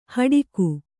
♪ haḍiku